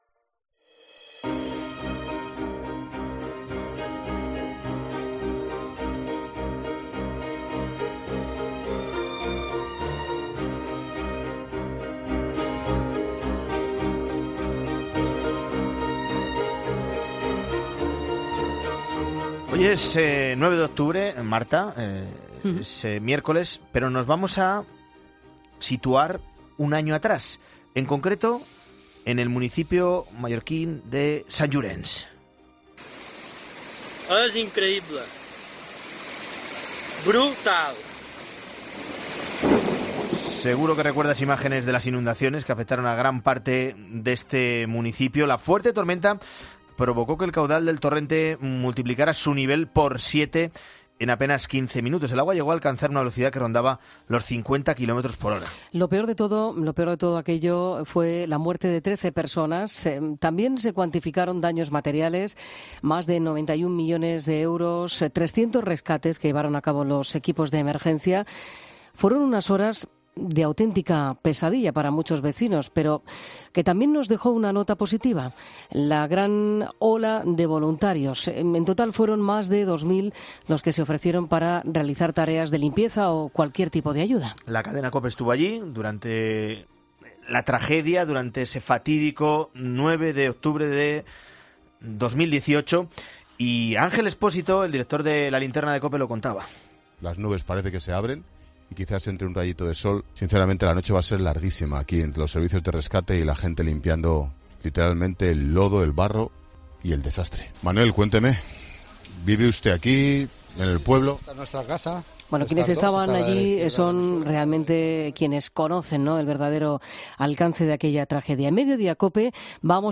En Mediodía COPE hemos hablado una de las protagonistas.